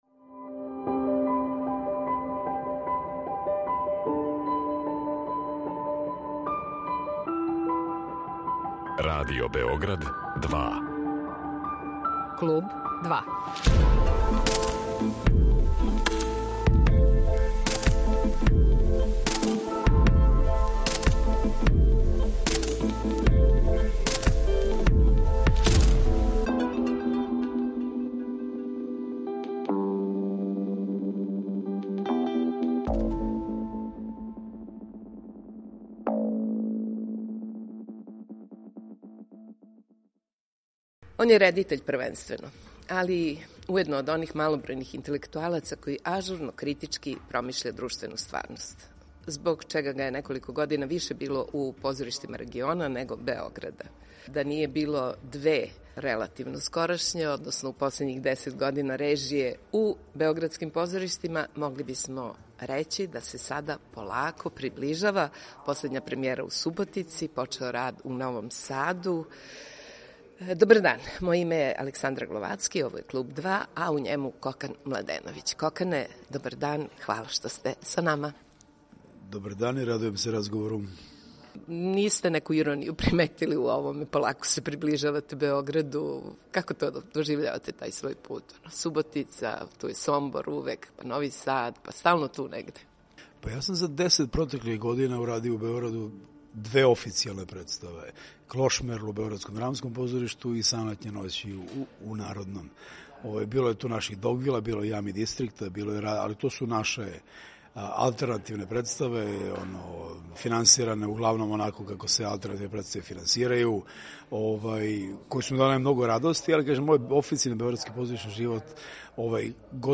Гост је Кокан Младеновић